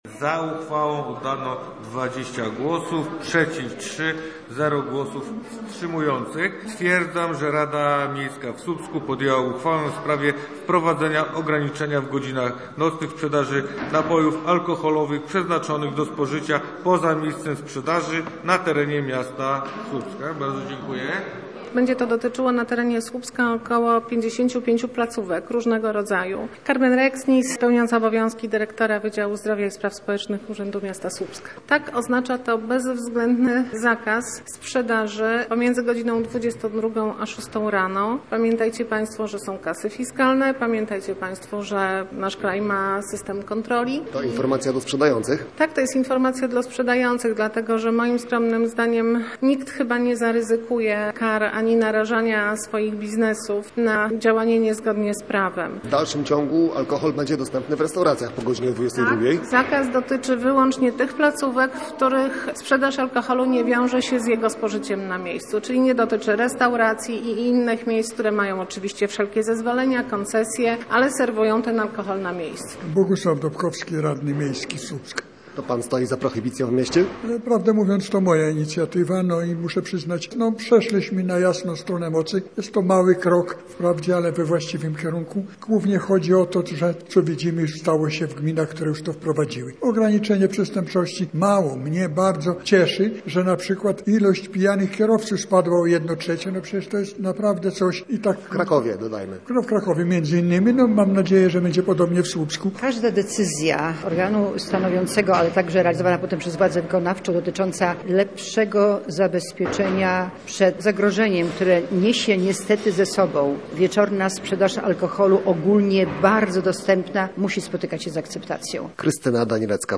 – Każde ograniczenie wieczornej sprzedaży alkoholu to dobry kierunek – komentuje dla Radia Gdańsk prezydent Słupska, Krystyna Danilecka-Wojewódzka.